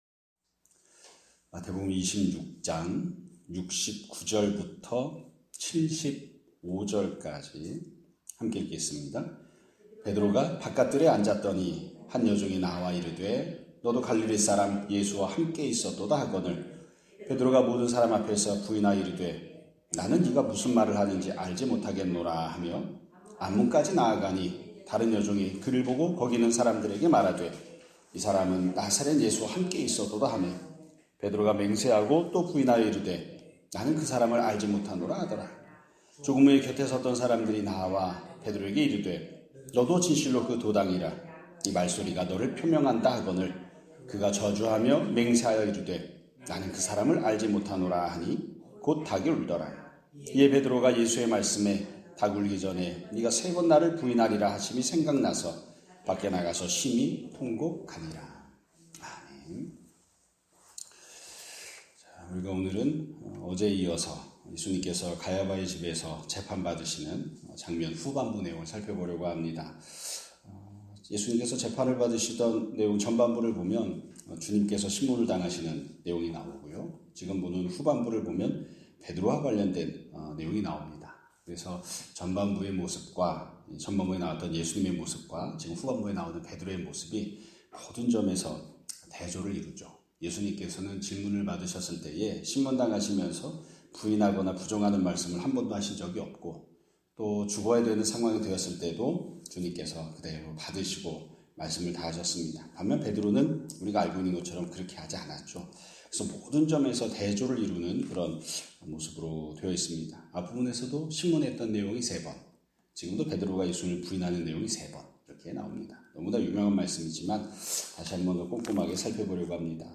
2026년 4월 10일 (금요일) <아침예배> 설교입니다.